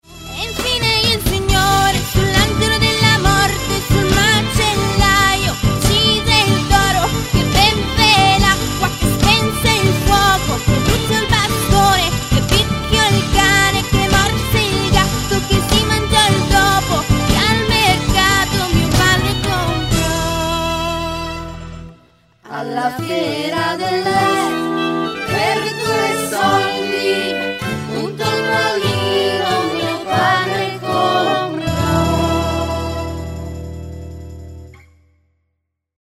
Microfono registrazione voci AKG C 414 XLII